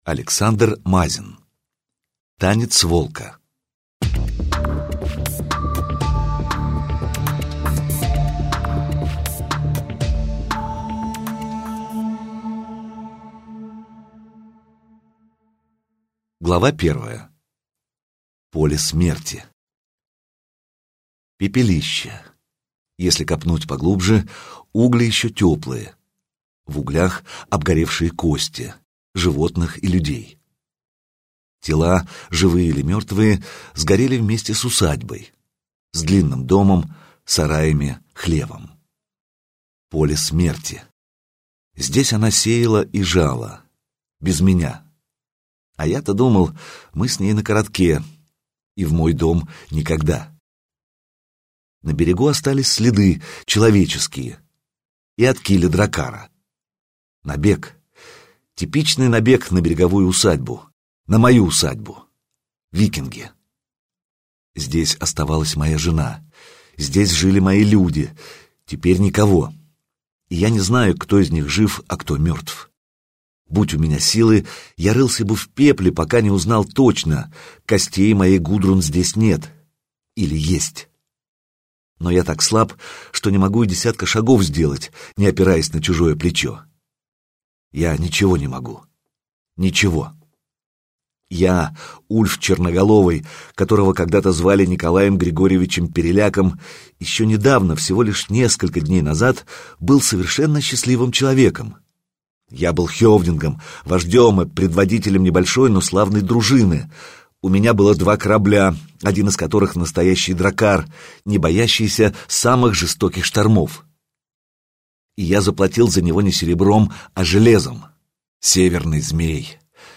Аудиокнига Танец волка - купить, скачать и слушать онлайн | КнигоПоиск